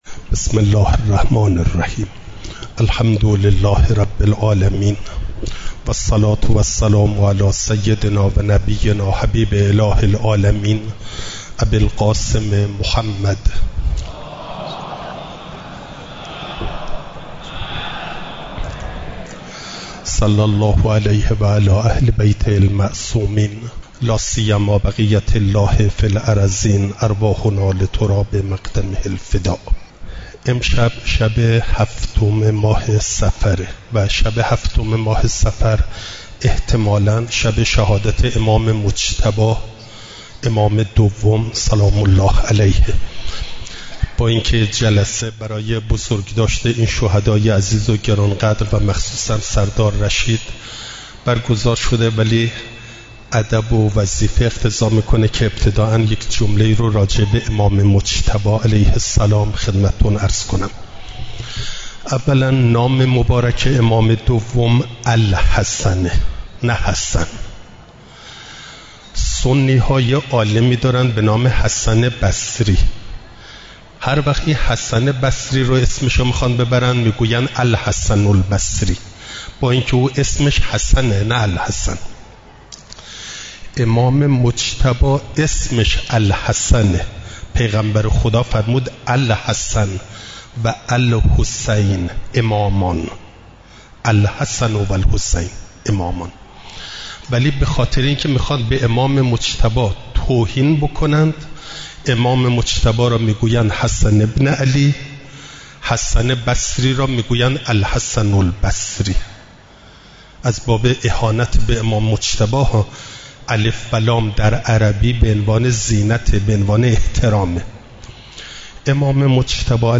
سخنرانی
حسینیه ثارﷲ دزفول برگزار شد.